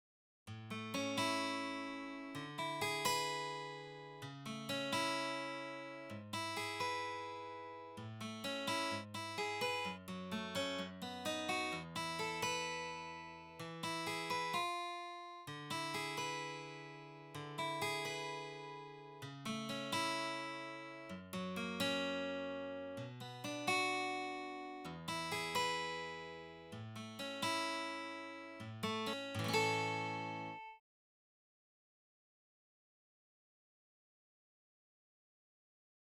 17（89ページ）ボイシング　ギター・２
17_Beethoven-A-Guitar-B.wav